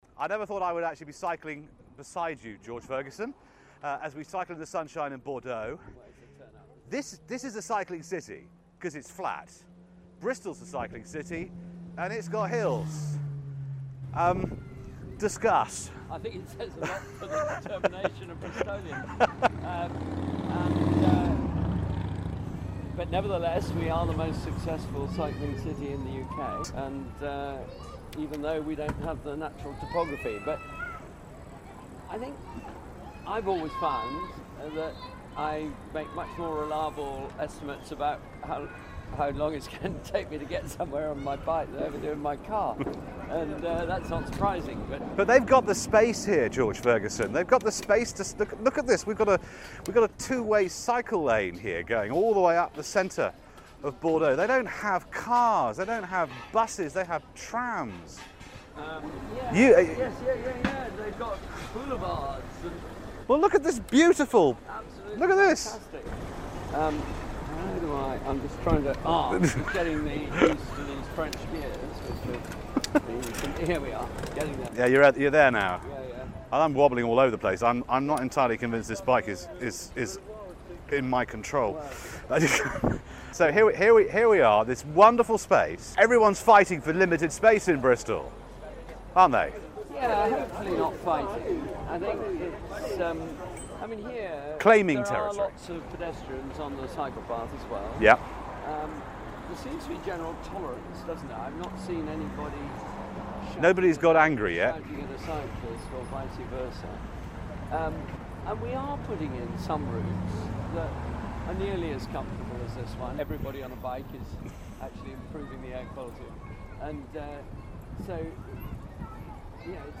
As part of BBC RB's visit to Bordeaux... Bristol's Mayor took time to cycle around the french city ...